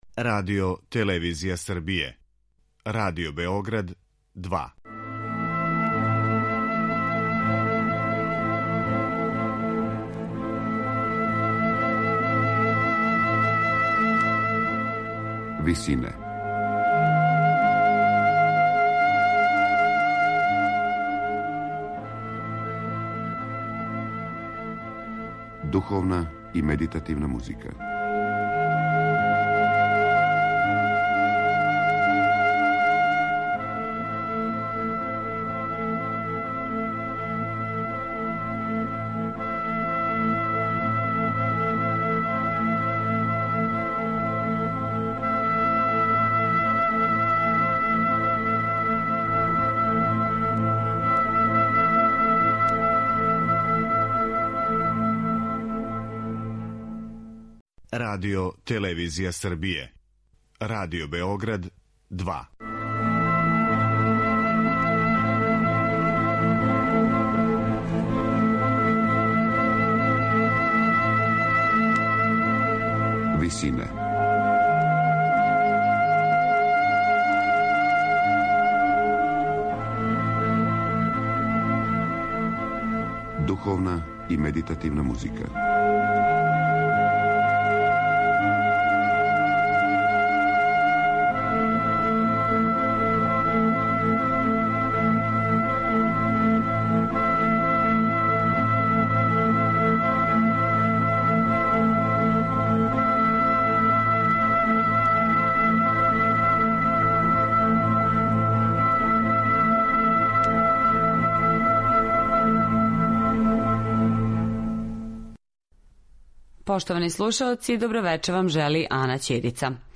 Ораторијум „Свети Тома”